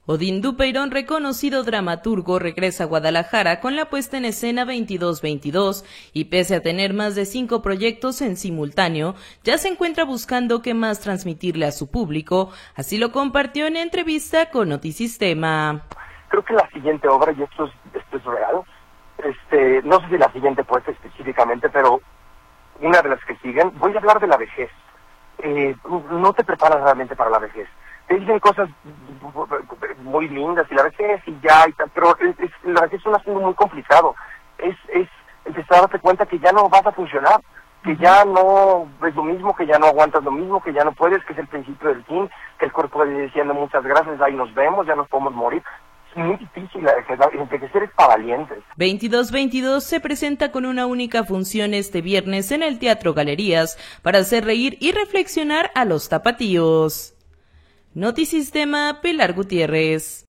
Odin Dupeyron, reconocido dramaturgo, regresa a Guadalajara, con la puesta en escena “Veintidós Veintidós”, y pese a tener mas de cinco proyectos en simultaneo, ya se encuentra buscando que más transmitirle a su publico, así lo compartió en entrevista con Notisistema.